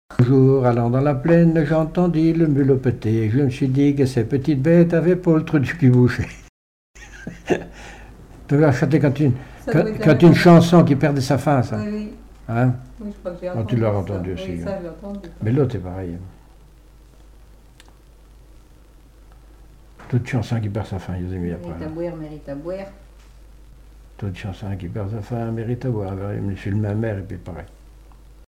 Catégorie Pièce musicale inédite